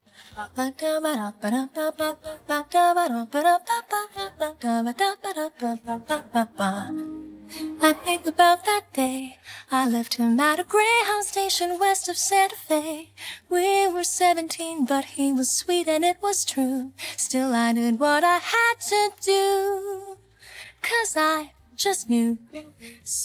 tmpd6_mx2pjday-of-sun-clip_vocals.wav